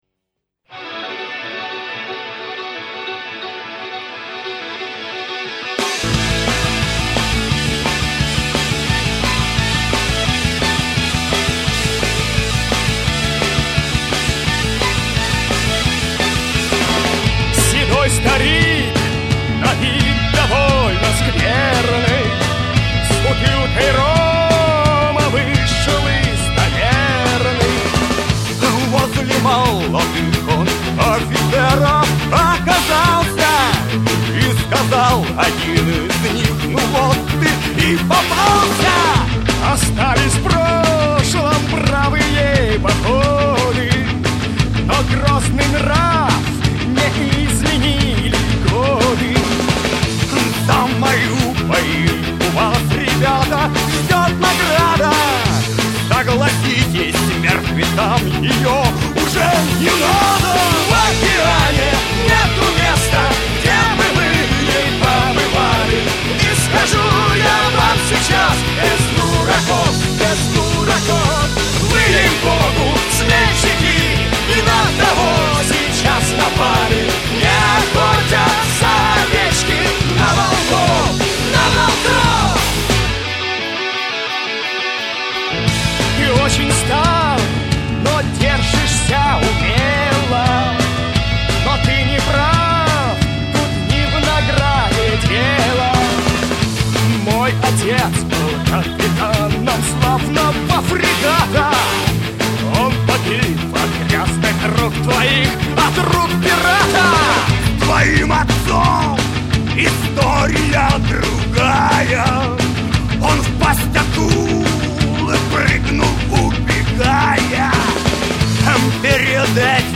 Главная » Онлайн Музыка » Рок